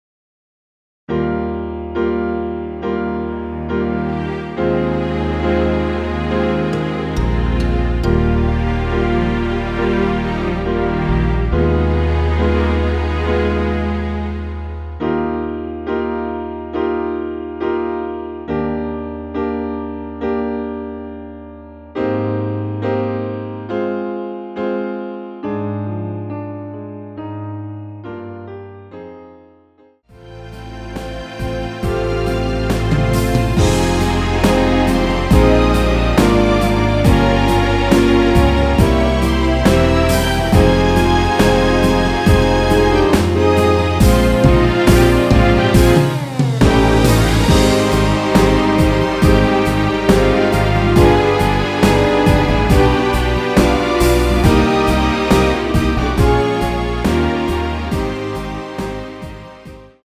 원키에서(-1)내린 MR입니다.
Eb
앞부분30초, 뒷부분30초씩 편집해서 올려 드리고 있습니다.
중간에 음이 끈어지고 다시 나오는 이유는